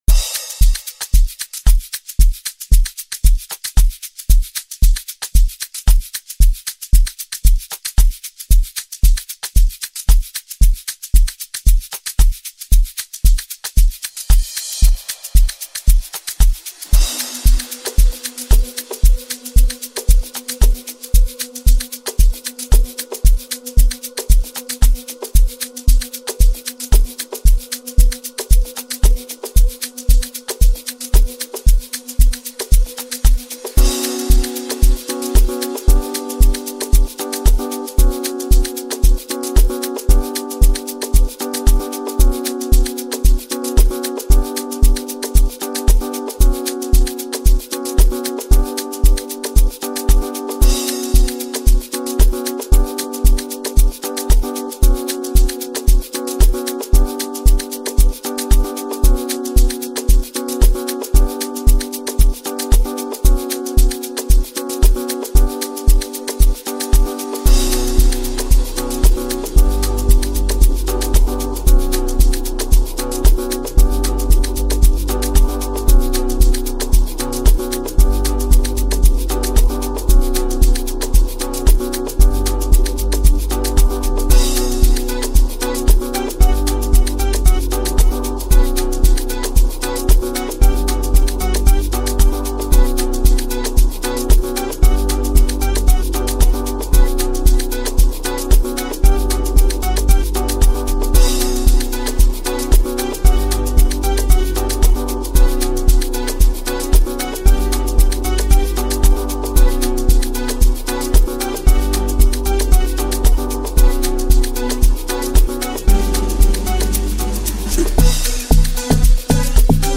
a track that blends rhythm and melody flawlessly